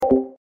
discord-mute.mp3